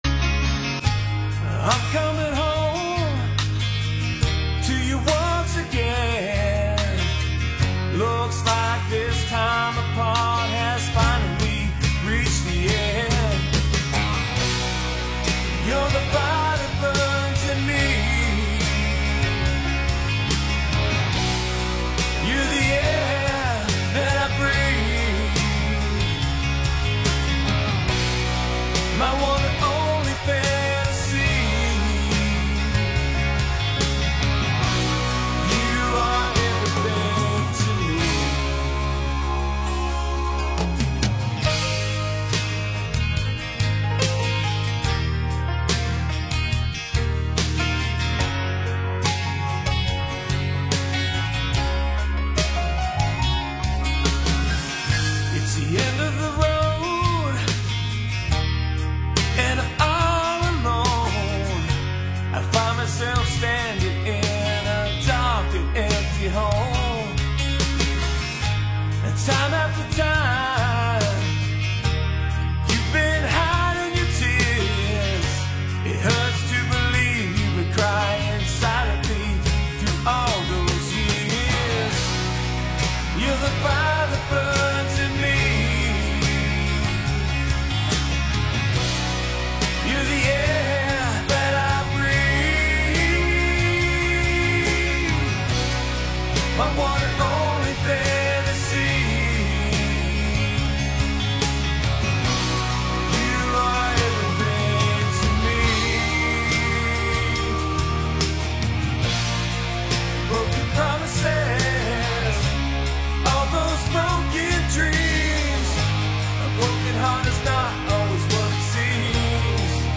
We’ll feature some of their music and tell where you can learn more.